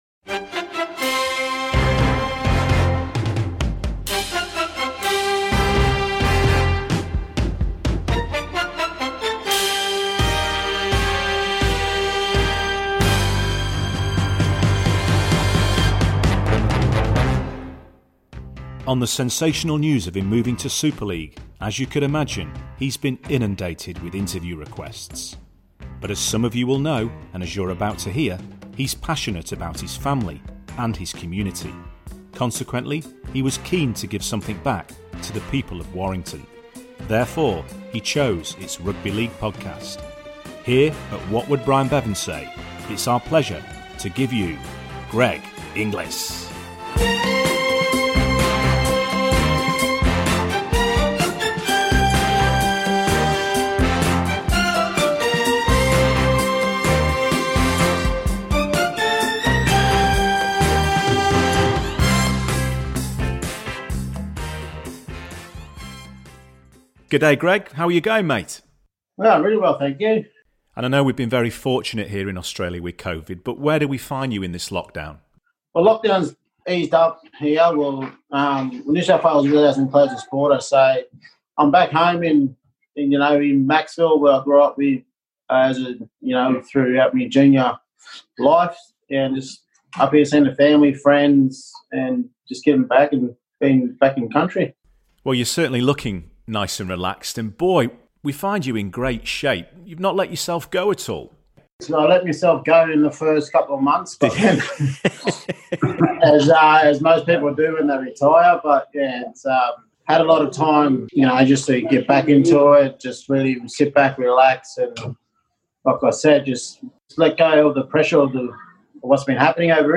What comes across in this revealing interview is that Greg Inglis is a sensitive man, whose passion for his local community has led to his new charity, but on the football field Greg's alter ego GI, now rested and recuperated, is fit and ready to go.